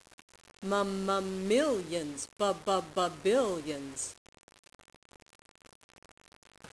This is a very simple rhythm to help a child figure out if they're working with a number in the millions or the billions.
Enter, the simple ditty.